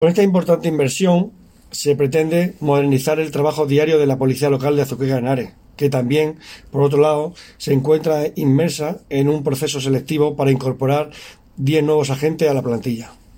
Declaraciones del concejal Antonio Expósito 2